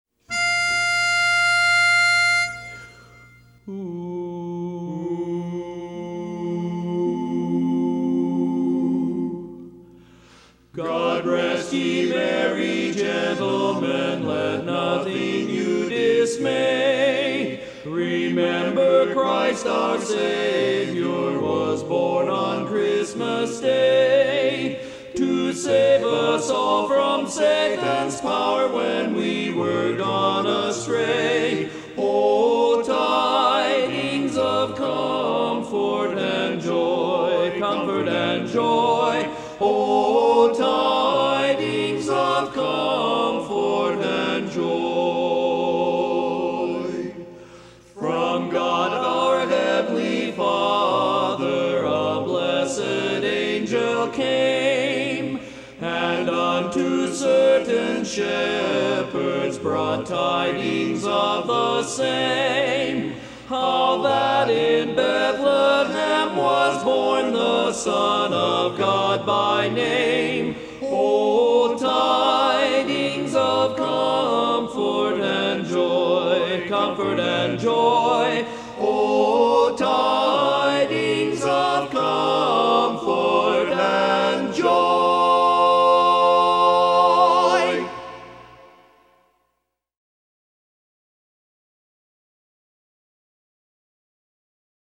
Christmas Songs
Barbershop
Lead